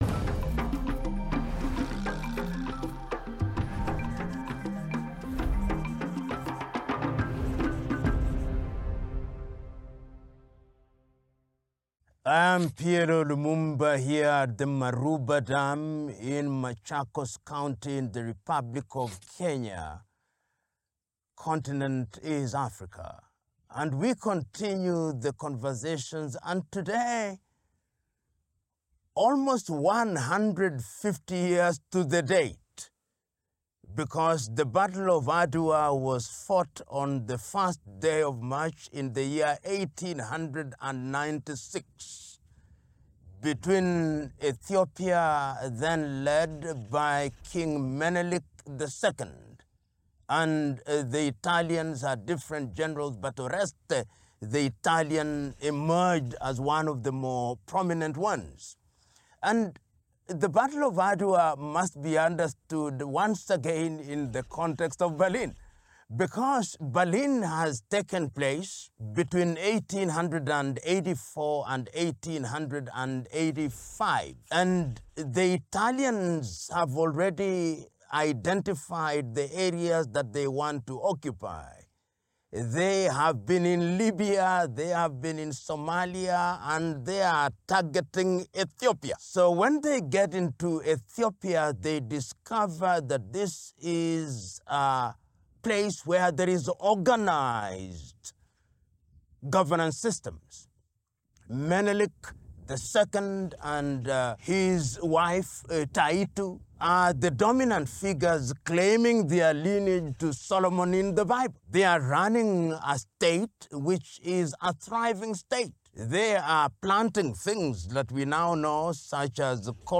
Taliban promises peace, amnesty, rights ‘within Sharia law’ & ‘narcotics-free’ Afghanistan in first intl media press conference
Taliban spokesman Zabihullah Mujahid made numerous sweeping claims in a Tuesday press conference saying the Taliban is focused on forming a government in Afghanistan, will observe women’s rights and has “pardoned” wartime enemies.